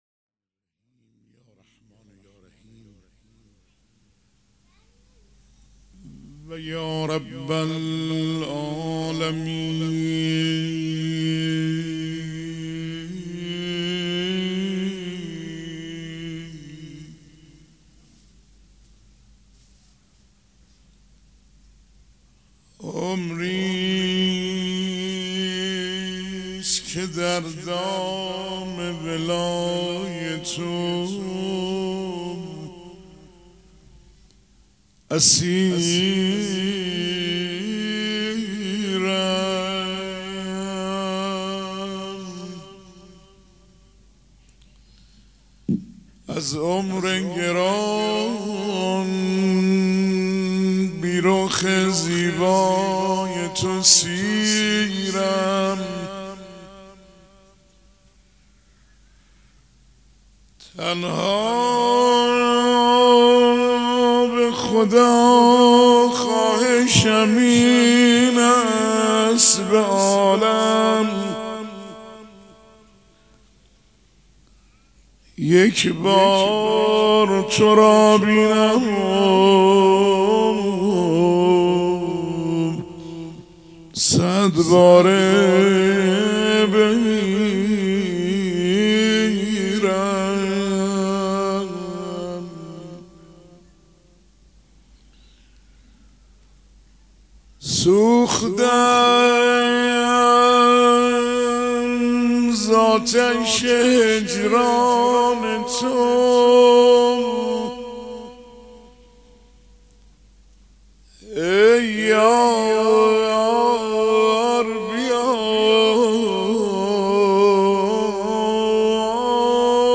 سبک اثــر پیش زمینه مداح حاج سید مجید بنی فاطمه
مراسم عزاداری شب چهارم